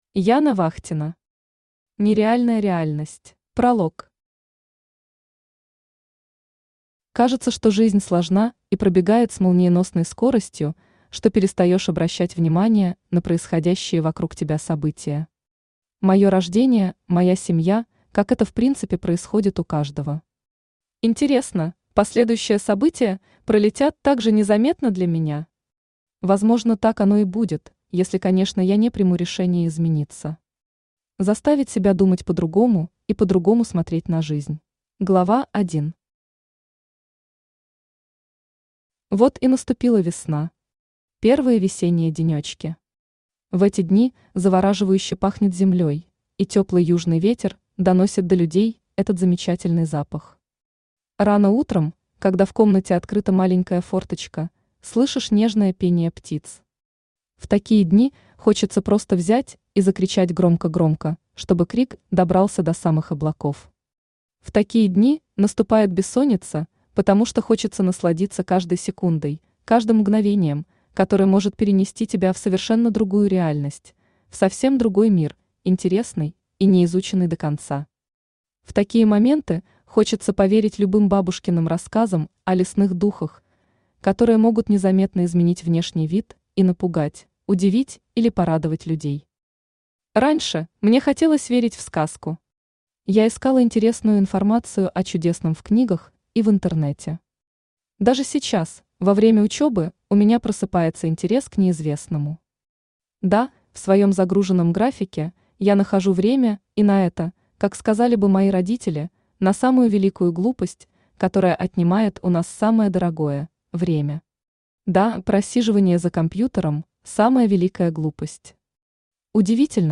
Aудиокнига Нереальная реальность Автор Яна Вахтина Читает аудиокнигу Авточтец ЛитРес.